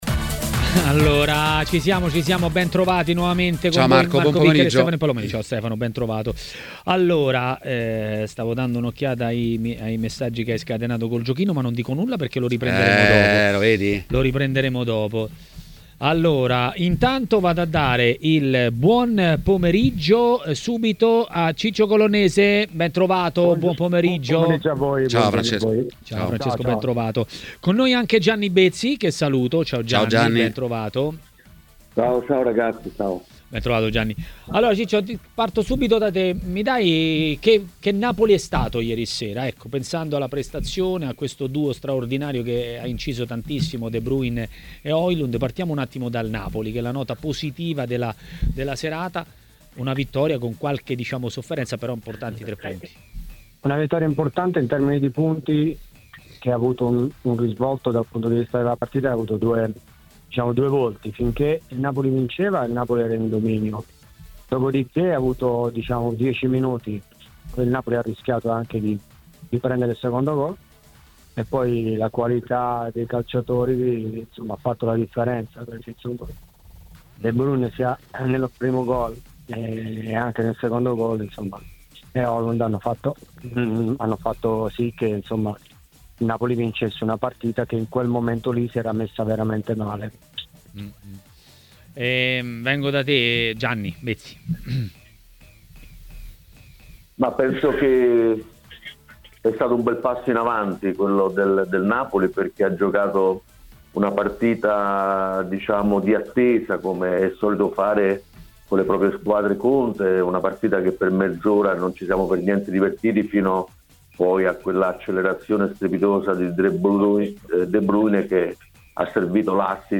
Ospite di Maracanà, nel pomeriggio di TMW Radio, è stato l'ex difensore Francesco Colonnese.